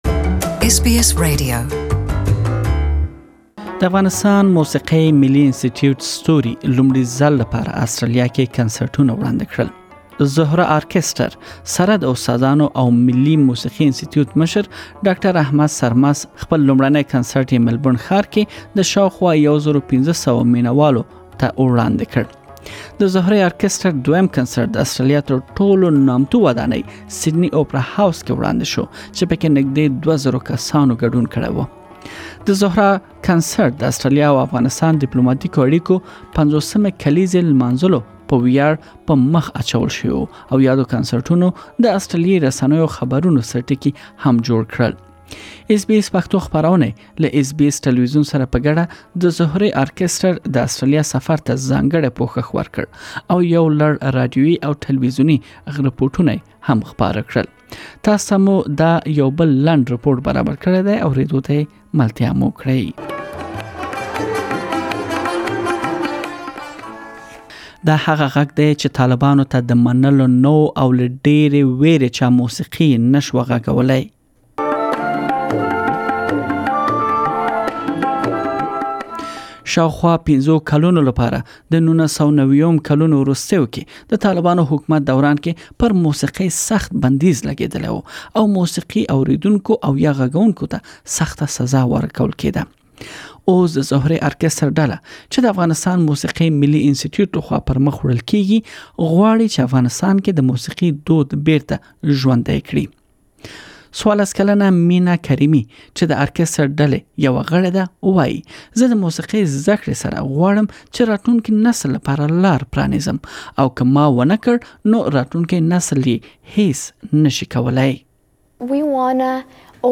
Afghanistan’s first all-female orchestral ensemble are taking to the stage for two special performances in Melbourne and Sydney. The players from Zohra [[Zor-rah]] are touring Australia for the first time to celebrate 50 years of diplomatic relations between Australia and Afghanistan. Please listen to the full report in Pashto.